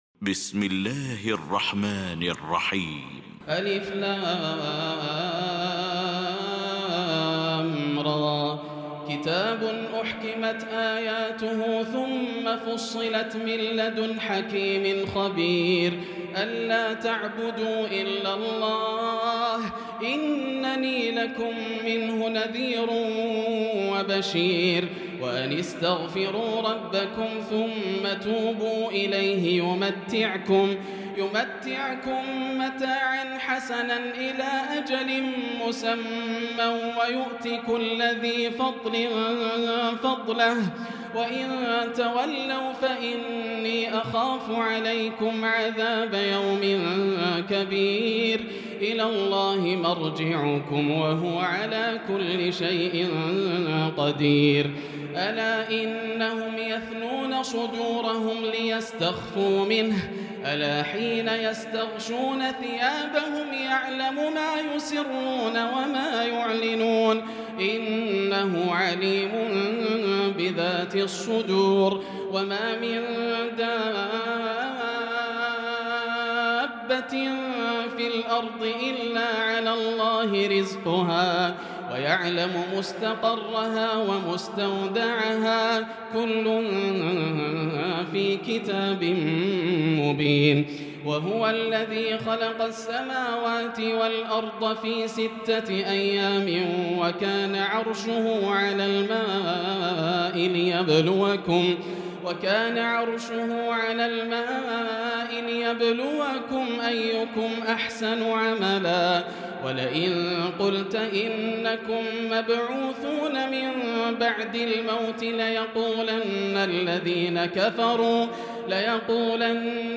المكان: المسجد الحرام الشيخ: سعود الشريم سعود الشريم فضيلة الشيخ ياسر الدوسري هود